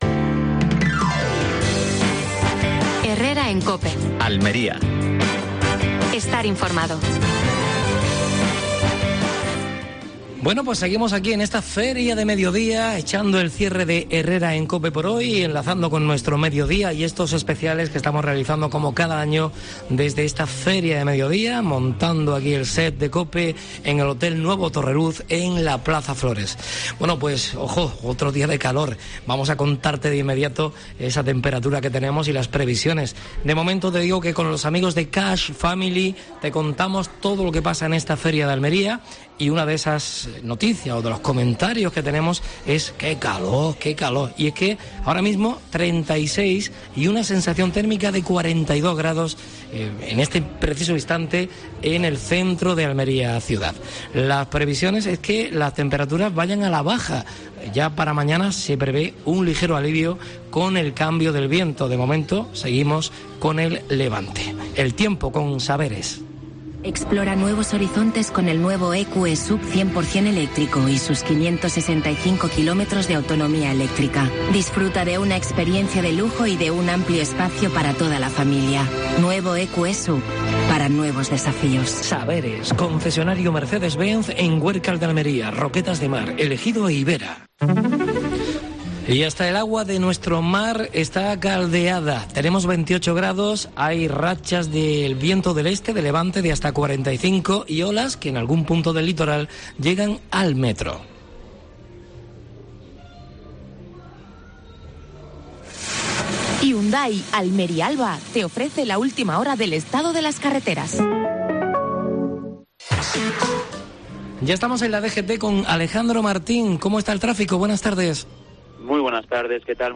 AUDIO: Programa especial de la Feria de Almería desde el Hotel Torreluz. Entrevista al Circo Quirós.